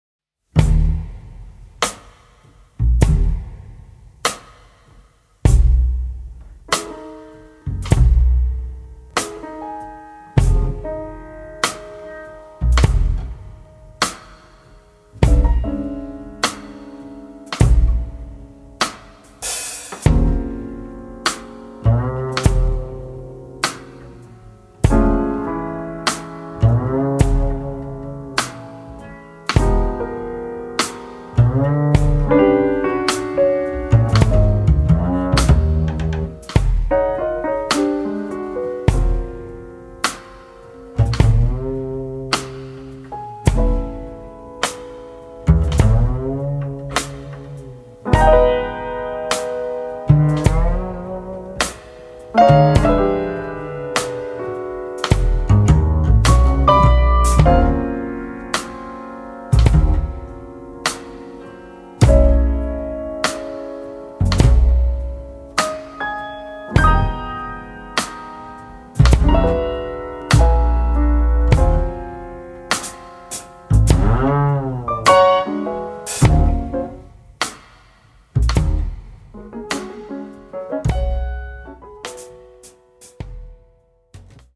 Recorded and mixed in Bergamo, Italy, in December 2008
pianoforte
contrabbasso
batteria